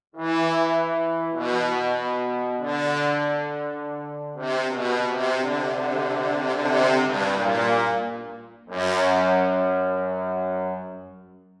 셰헤라자데 오프닝, 트롬본 베이스